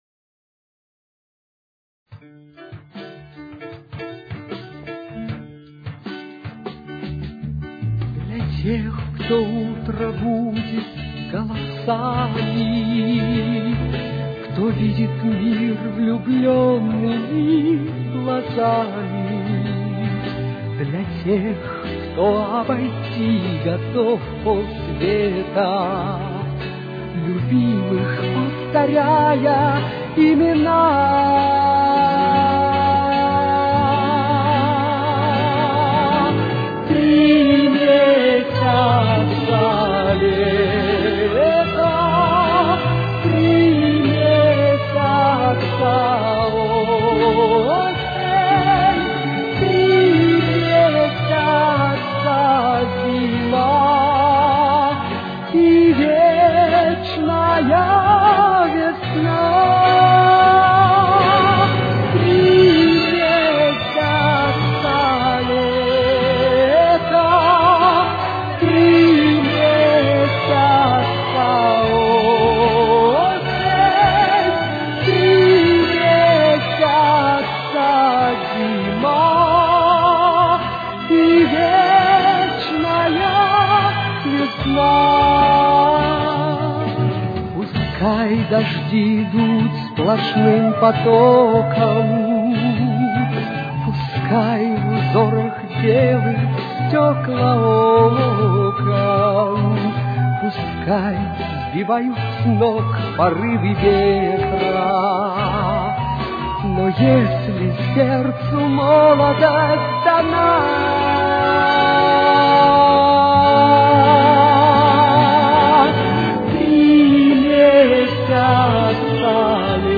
Тональность: Ре мажор. Темп: 78.